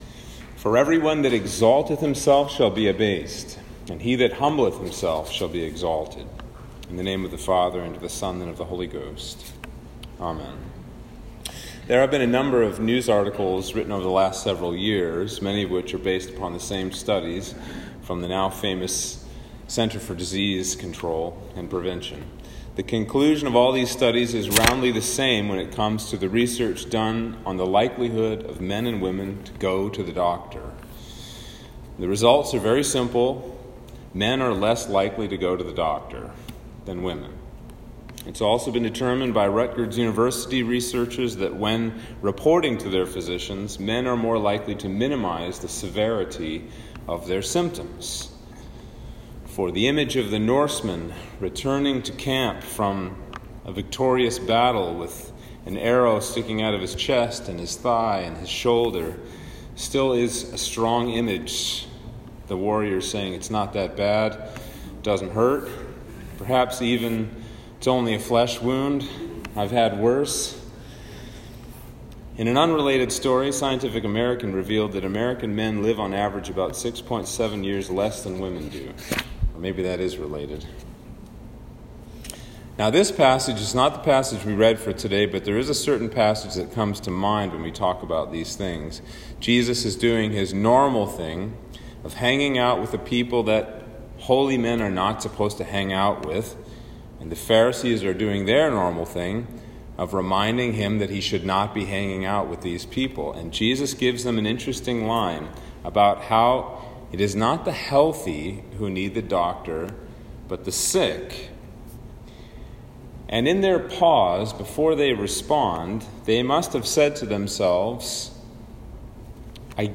Sermon for Trinity 11